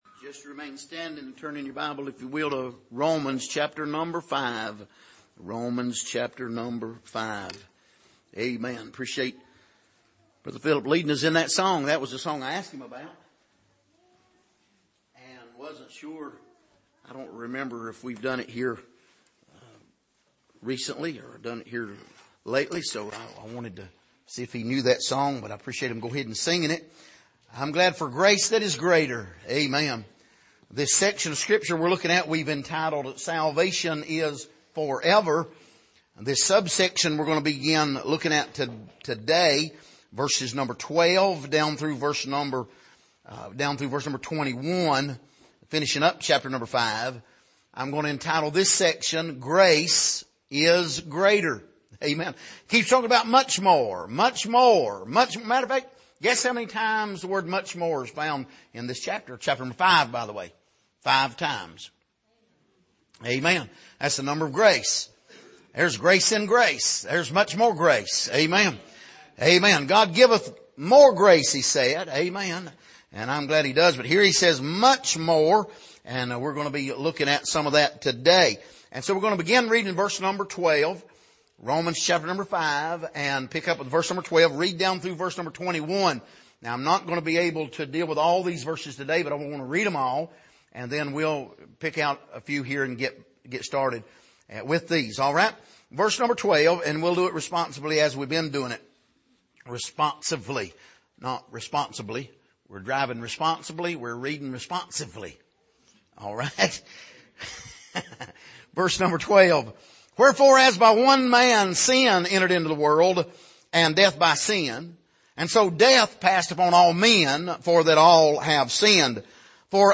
Passage: Romans 5:12-21 Service: Sunday Morning